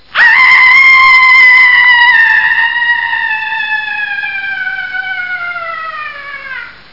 home *** CD-ROM | disk | FTP | other *** search / Horror Sensation / HORROR.iso / sounds / iff / womanscr.snd ( .mp3 ) < prev next > Amiga 8-bit Sampled Voice | 1992-09-02 | 81KB | 1 channel | 11,697 sample rate | 7 seconds
womanscr.mp3